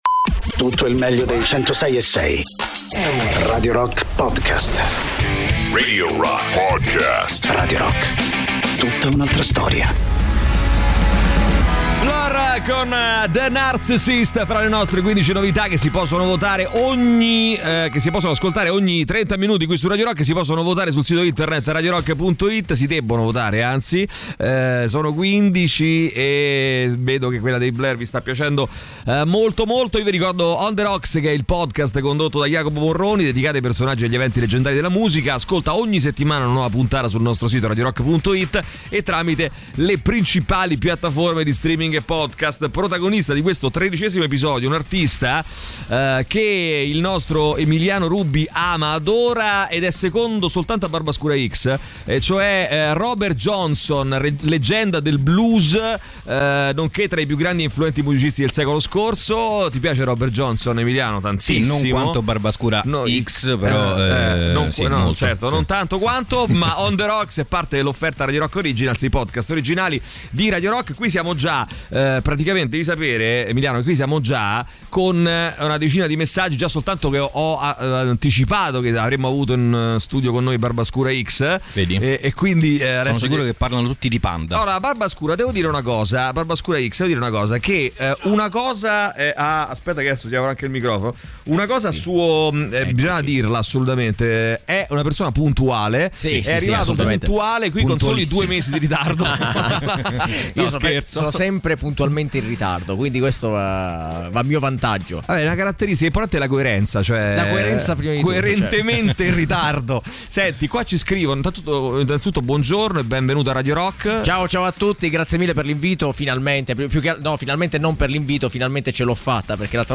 Interviste: Barbascura X (08-06-23)